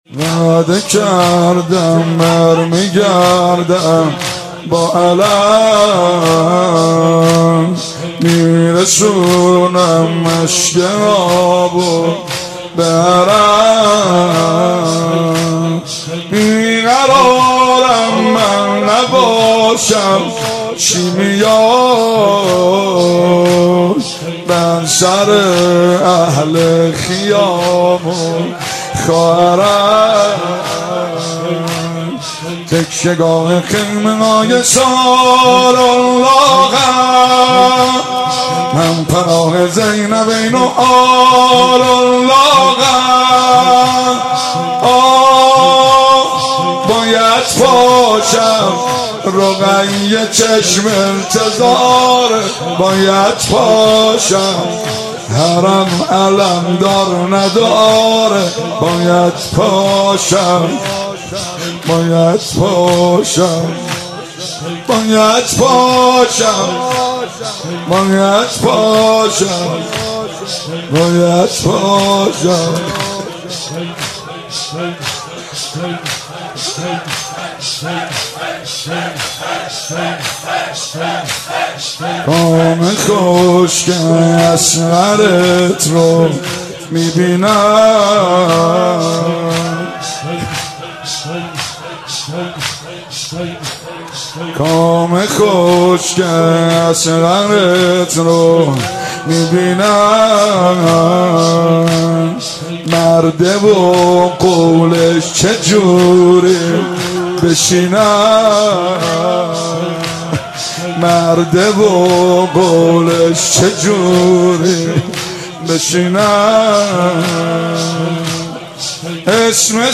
مراسم شب تاسوعای محرم الحرام سال 1395